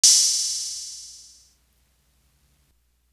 Crashes & Cymbals
Lose Ya Life Crash.wav